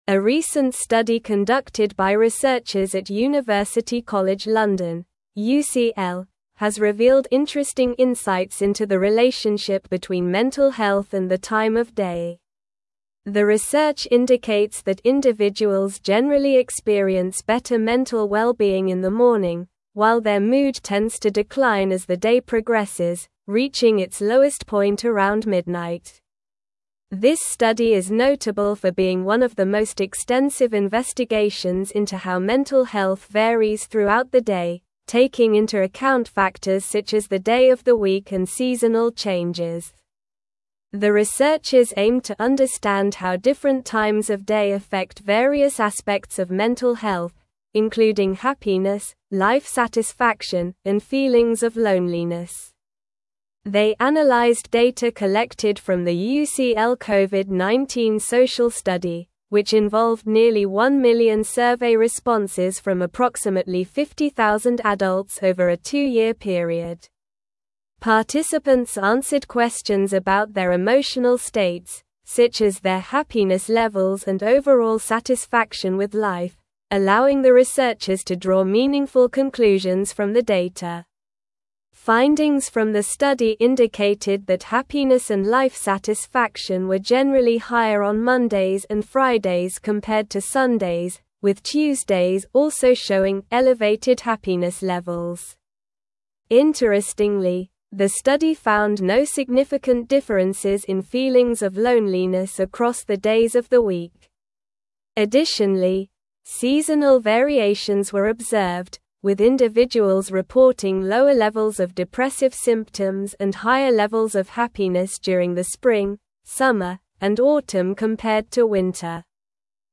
Slow
English-Newsroom-Advanced-SLOW-Reading-Mental-Health-Declines-Throughout-the-Day-Study-Finds.mp3